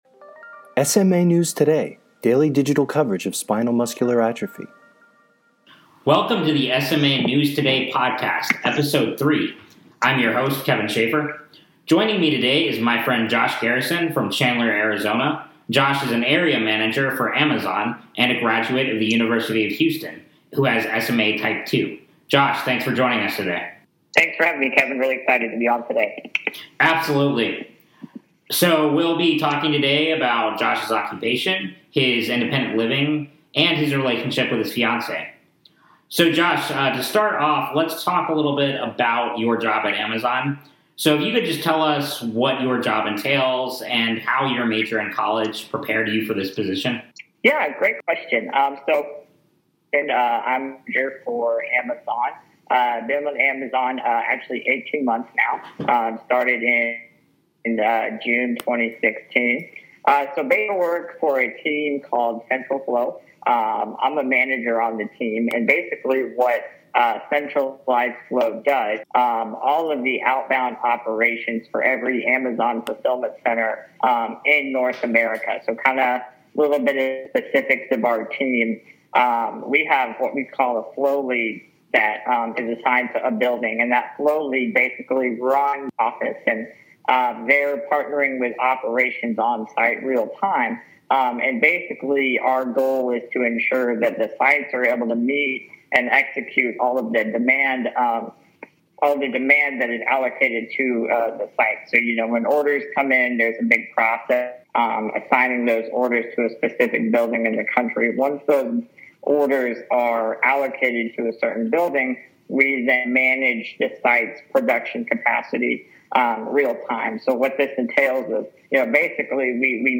SMA News Today Podcast 3 - Interview